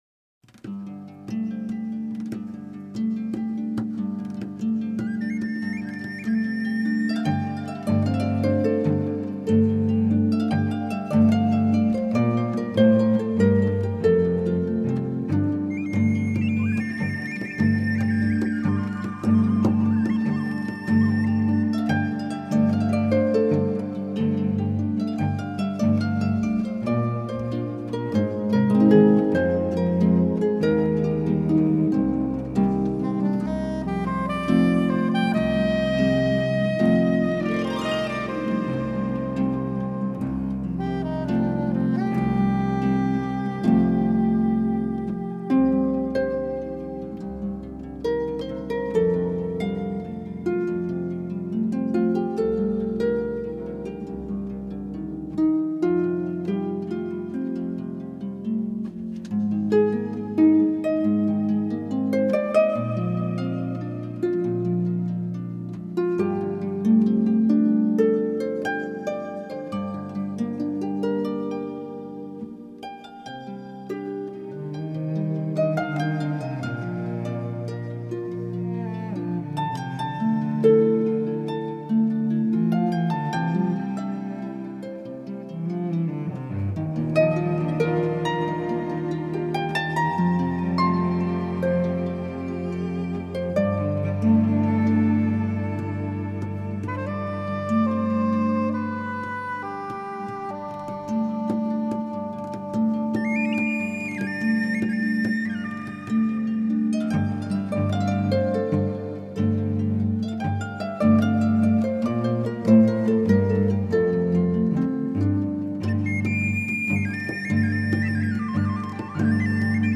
专辑类型：竖琴演奏
竖琴演译天堂之音